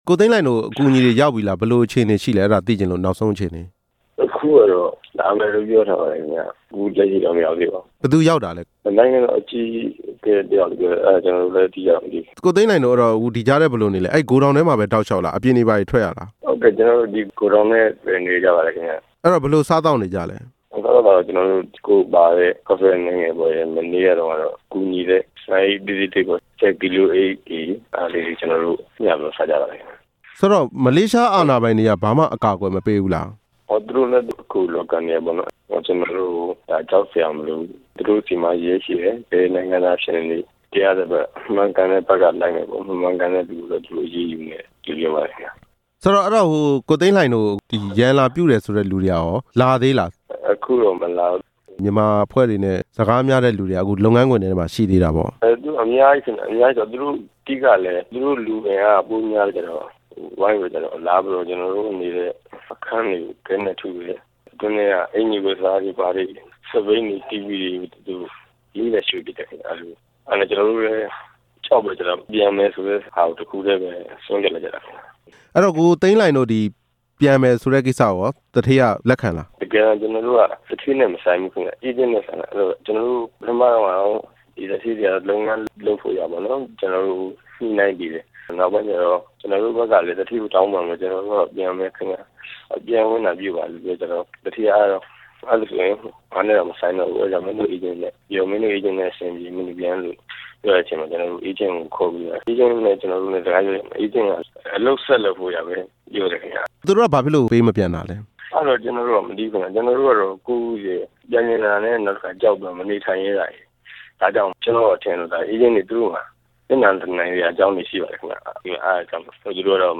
မလေးရှားမှာ ရန်ပြုခံရတဲ့ မြန်မာတွေရဲ့ အခြေအနေကို မေးမြန်းချက်